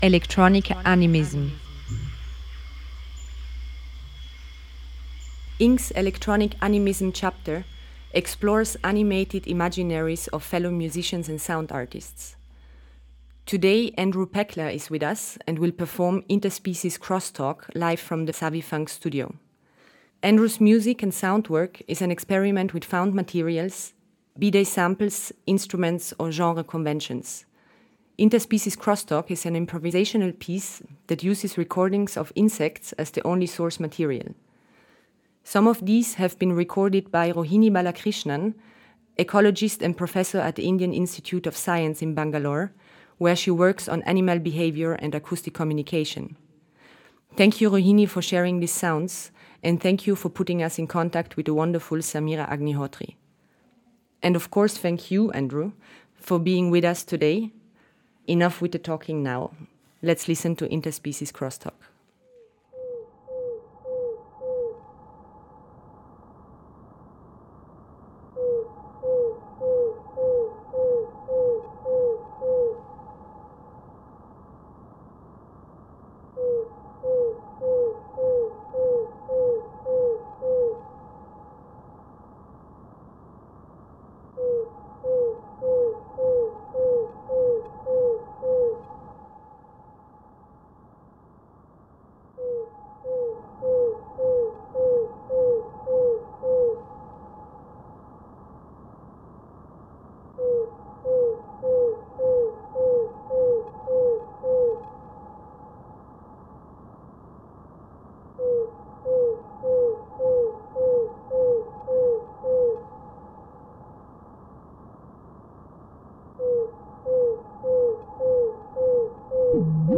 improvisational piece
uses recordings of insects as the only source material.
Avant-Garde Noise